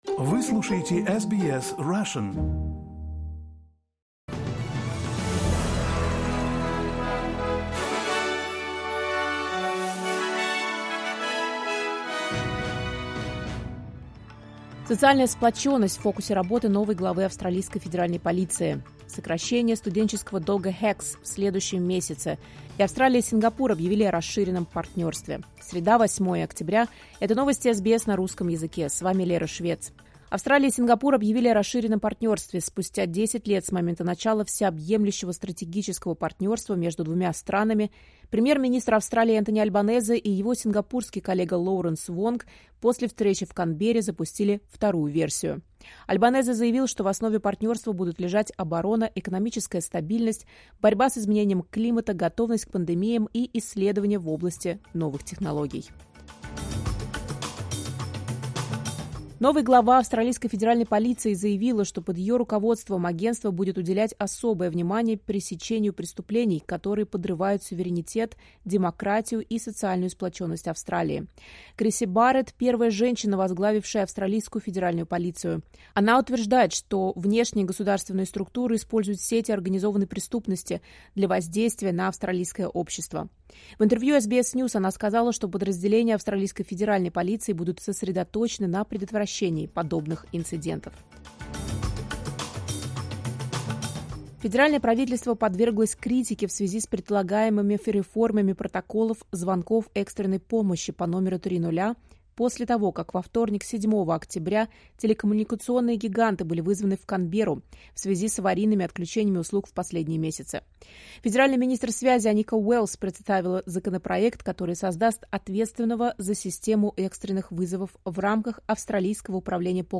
Новости SBS на русском языке — 08.10.2025